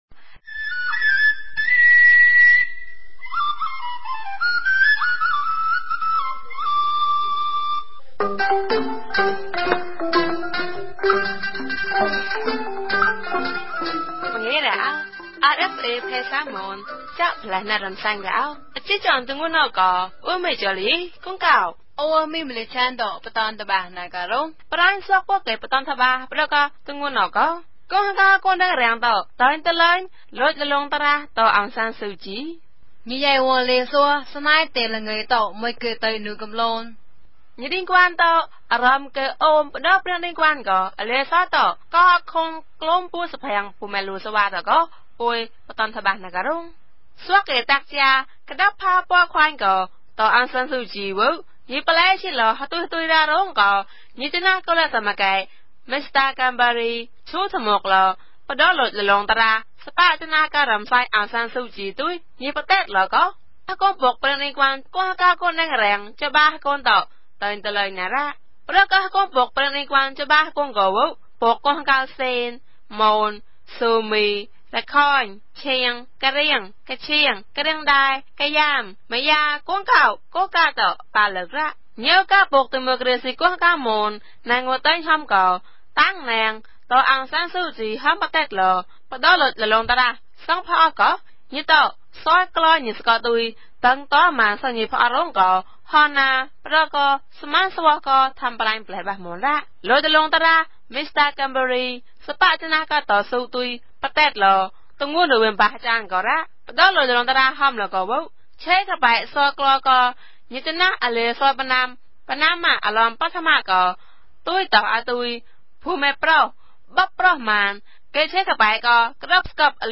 မြန်ဘာသာ အသံလြင့်အစီအစဉ်မဵား